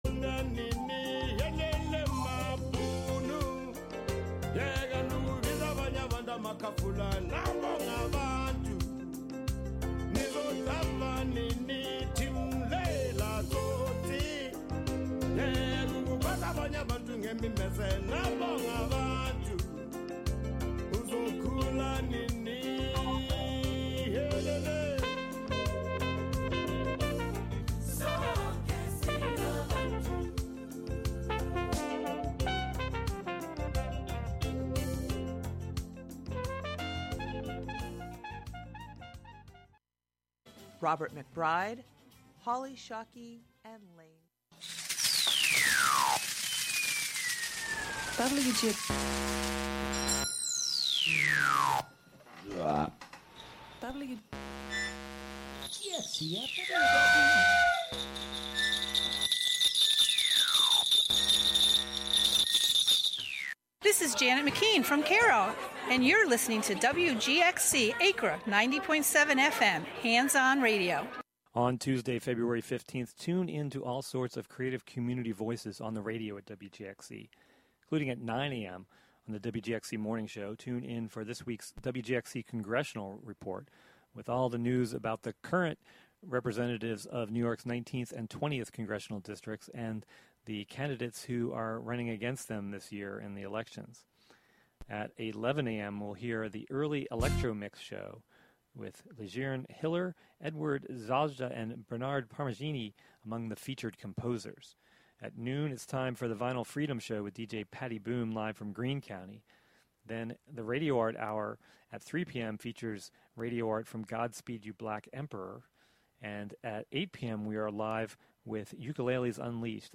A celebration of Valentine’s Day with romantic music